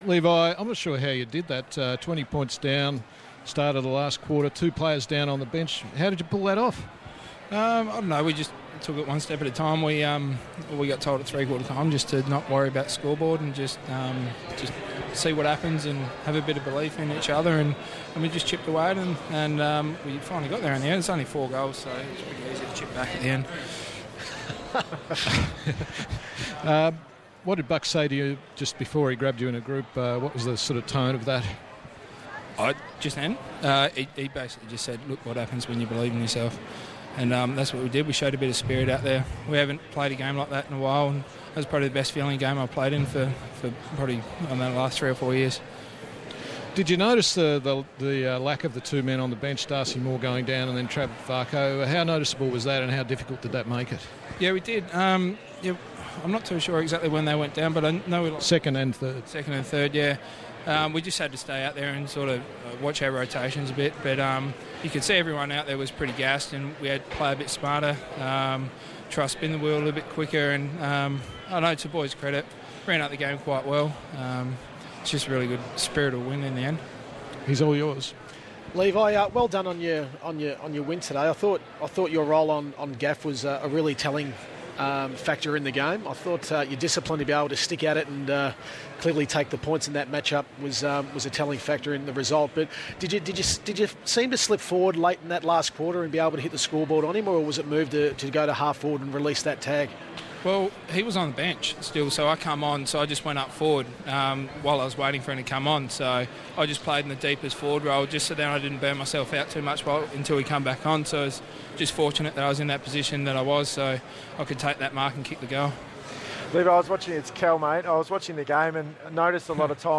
Radio: Levi Greenwood on ABC
Listen to Levi Greenwood join ABC Grandstand after Collingwood's eight-point win over West Coast.